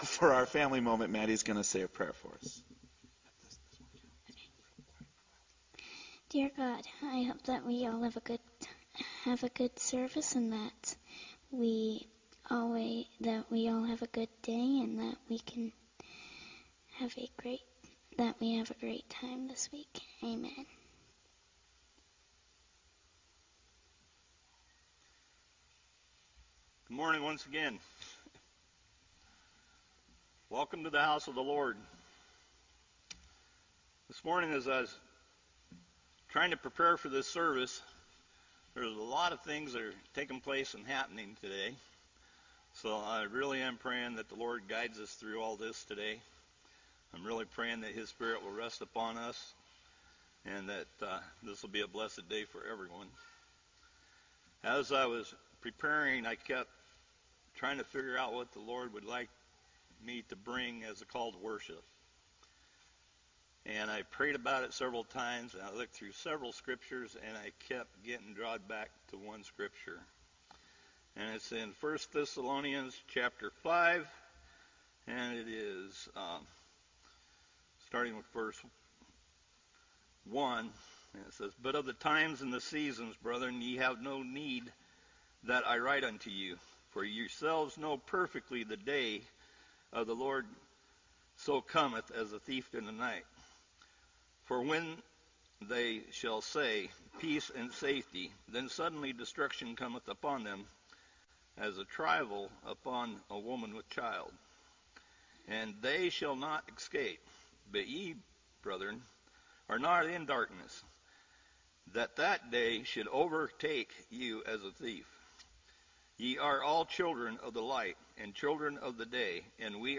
Song Service
Special Music
Sermon
Audio-Full Service